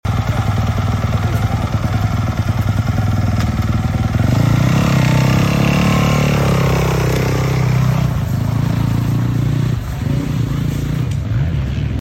Yamaha YZ